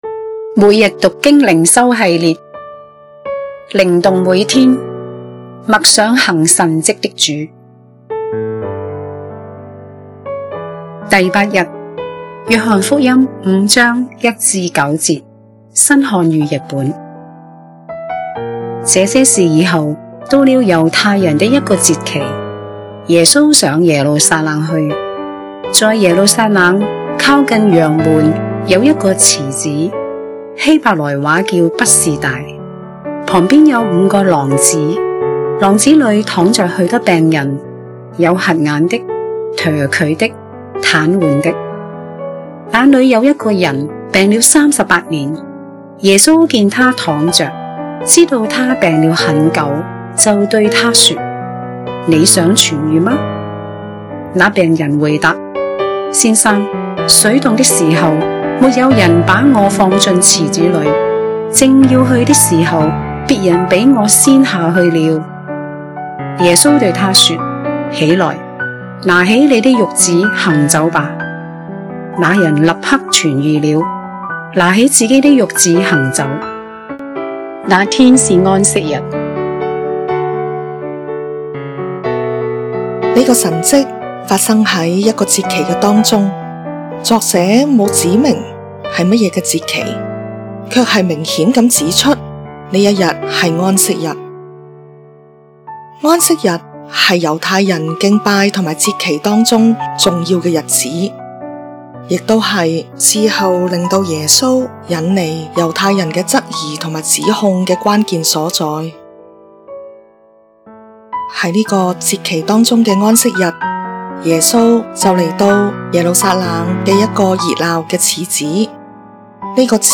經文閱讀